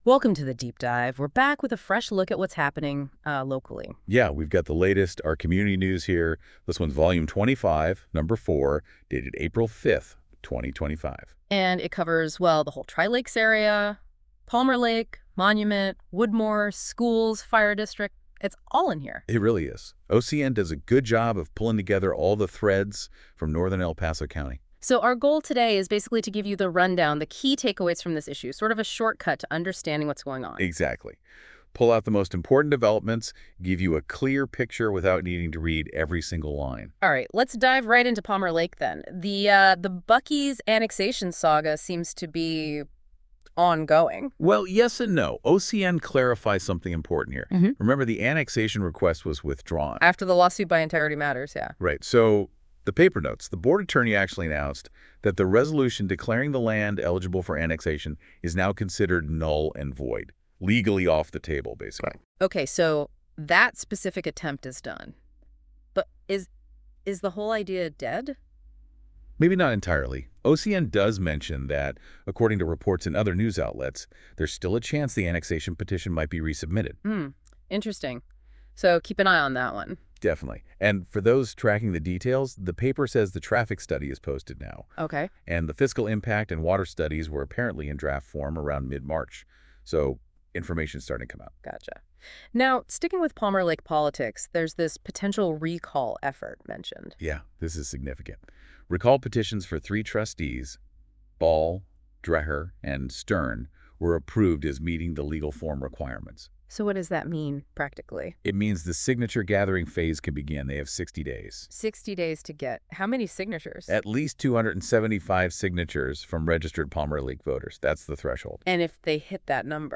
Vol. 25 No. 5 – May 3, 2025 Highlights (AI generated)
This podcast summary of our May 2025 issue was generated using an AI tool and has not been fact-checked in every detail by OCN volunteers.